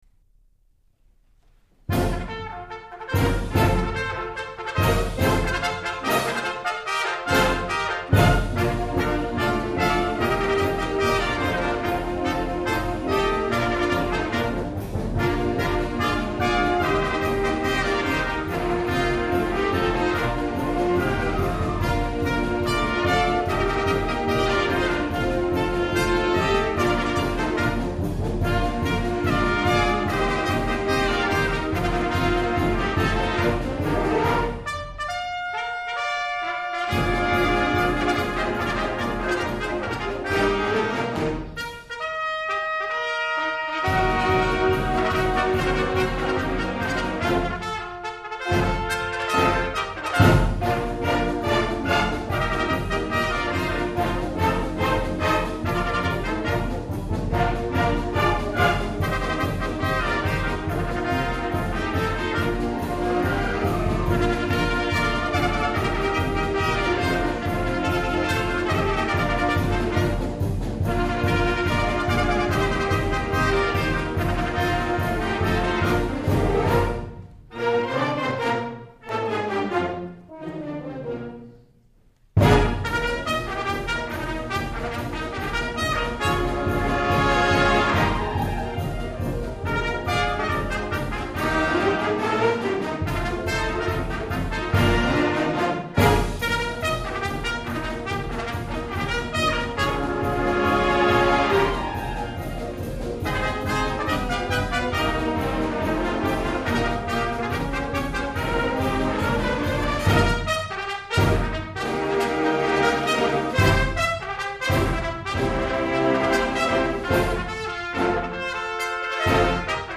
High School Instrumental Ensemble